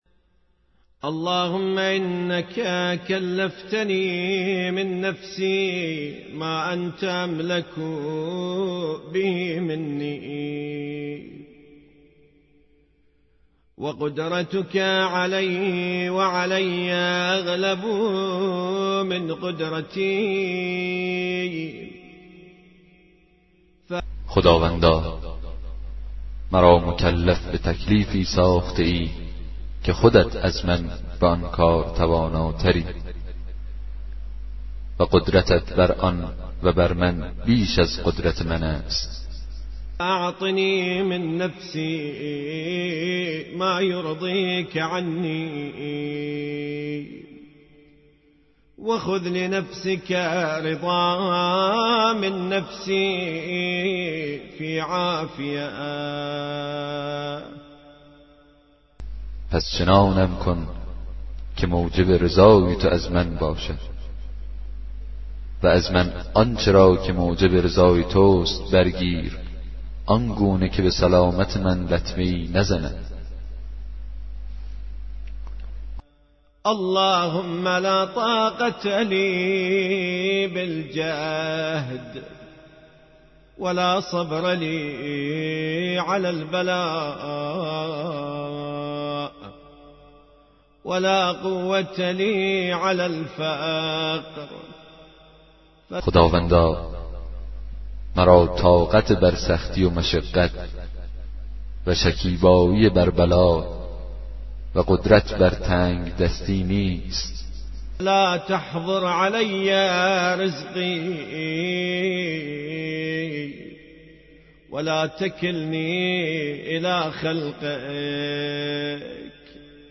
کتاب صوتی دعای 22 صحیفه سجادیه
به همراه ترجمه فارسی